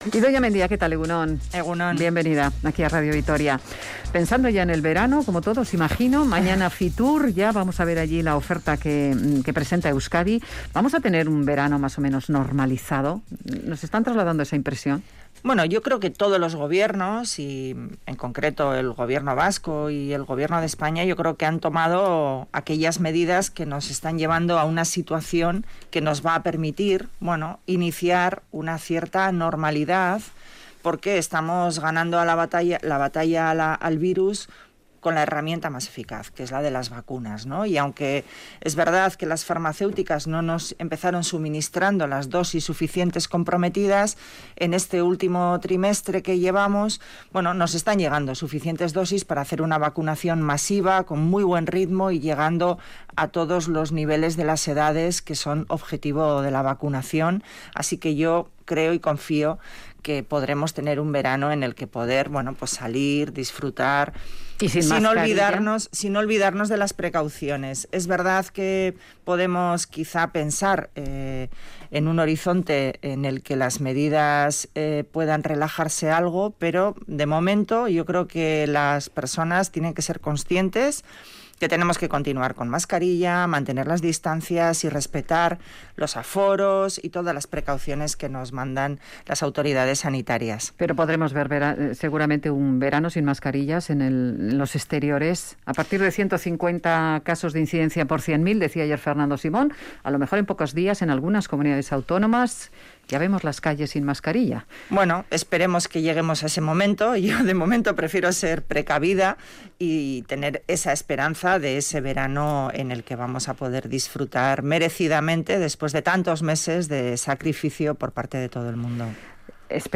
Audio: Entrevistada en Radio Vitoria, la vicelehendakari y consejera de Trabajo y Empleo, Idoia Mendia, apuesta por orientar a los jóvenes hacia la formación